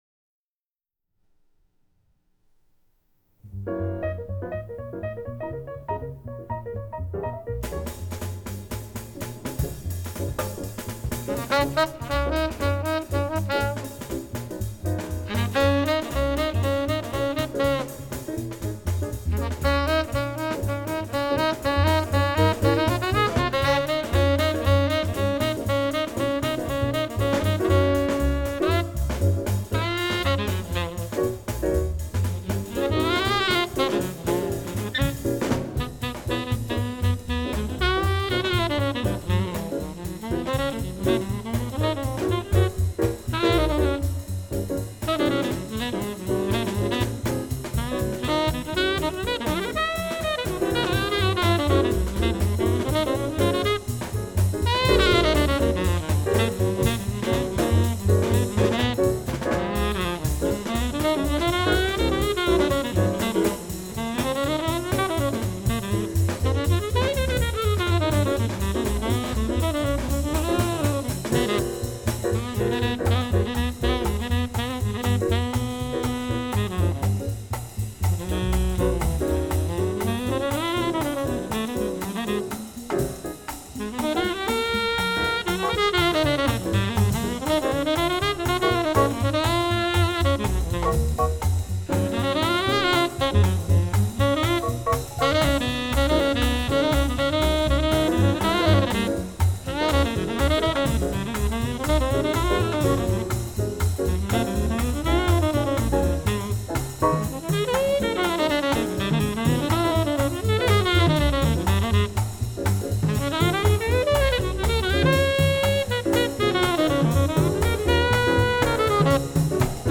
Tenor Saxophone
Bass
Drums
Piano